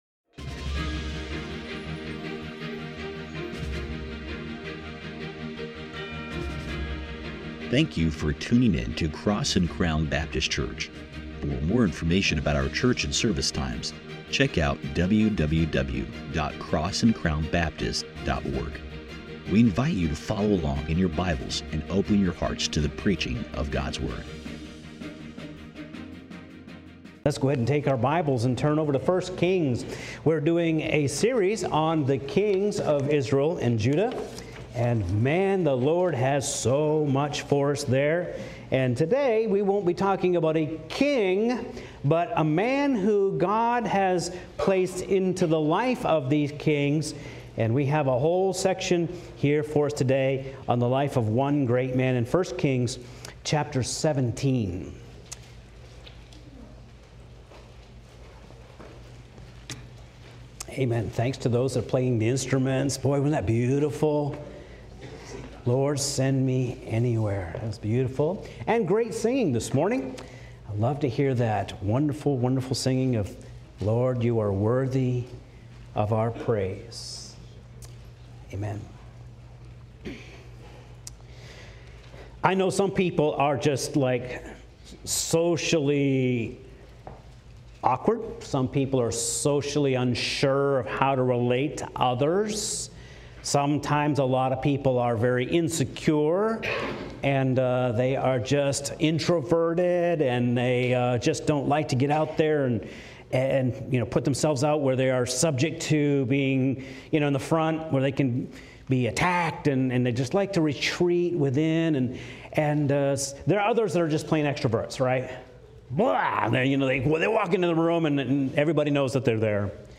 Sermons | Cross and Crown Baptist Church